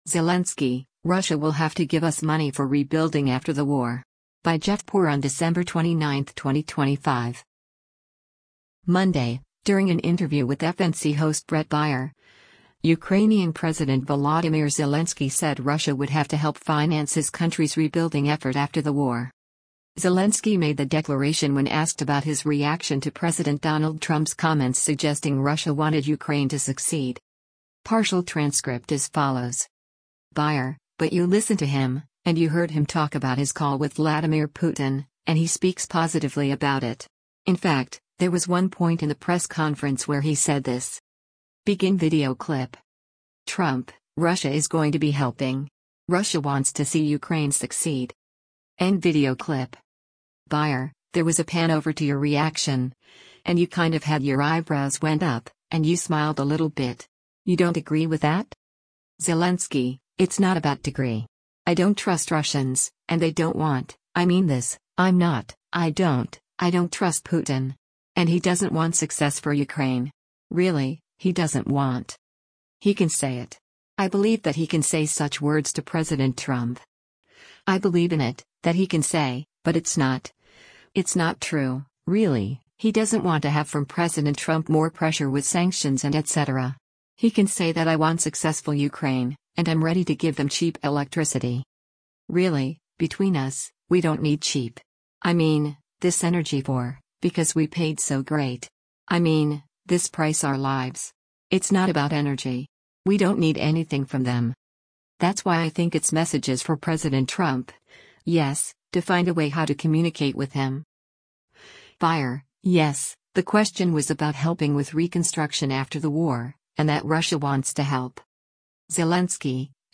Monday, during an interview with FNC host Bret Baier, Ukrainian President Volodymyr Zelensky said Russia would “have to” help finance his country’s rebuilding effort after the war.